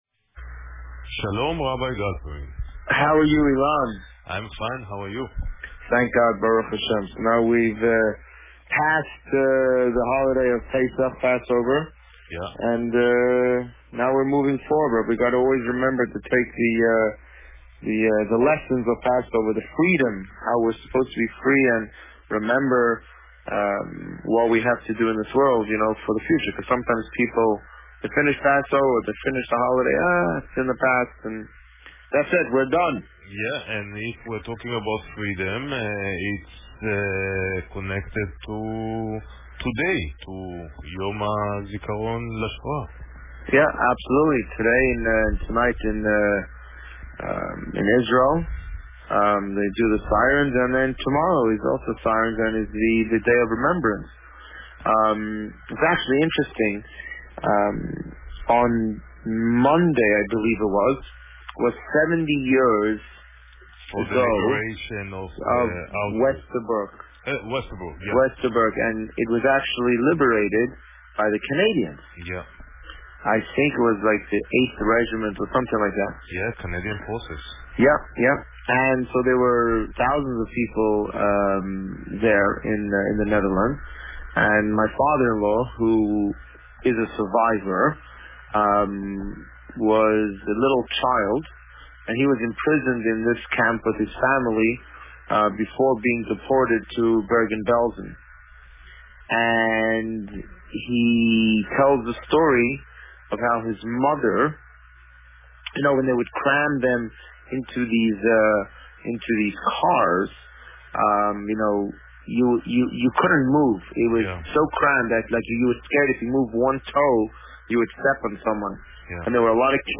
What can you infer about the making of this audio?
This week, the Rabbi recorded a segment on Wednesday evening for broadcast on Thursday. This segment covered Parasha Shemini and this week's Yom Hashoah celebrations. Listen to the interview here.